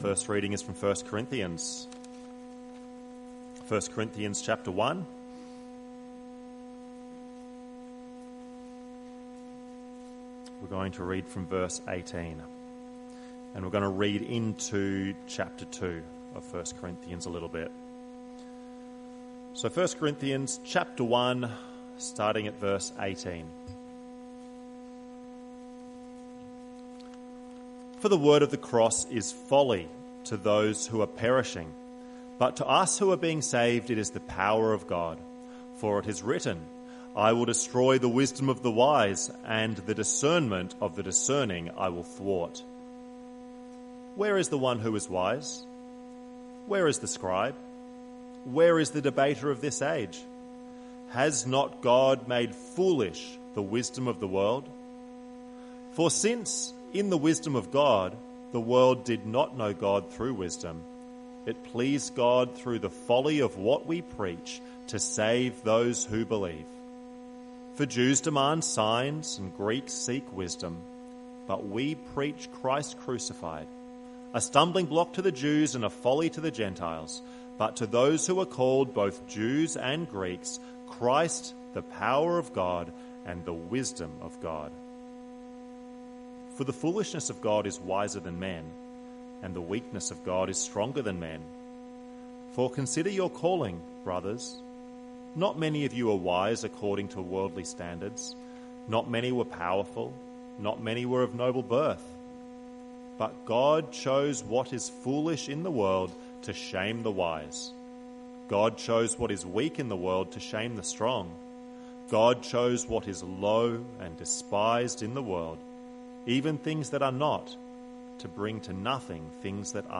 Close Log In using Email Jan 25, 2026 The Cross and Salvation (Galatians 1) MP3 SUBSCRIBE on iTunes(Podcast) Notes Sunday Morning - 25th January 2026 Readings: 1 Corinthians 1:18 - 2:5 Galatians 1:3-5